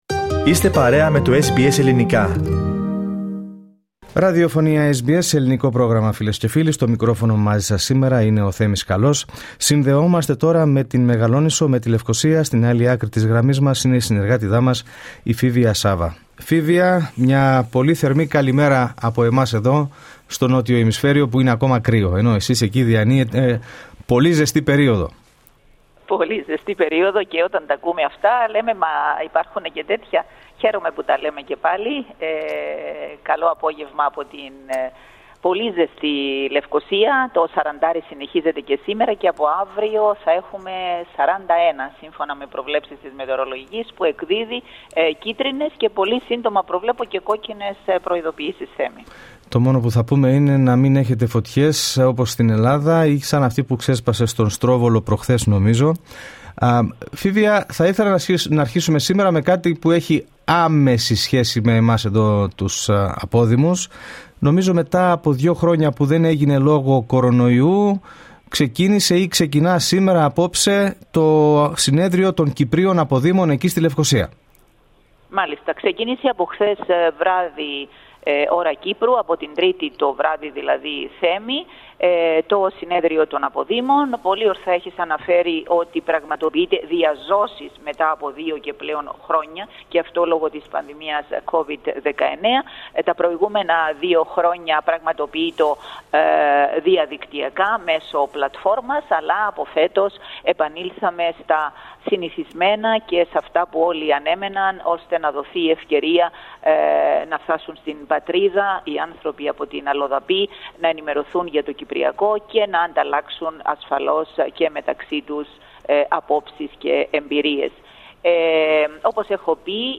Ακούστε ολόκληρη την ανταπόκριση από την Κύπρο, πατώντας το σύμβολο στο μέσο της κεντρικής φωτογραφίας.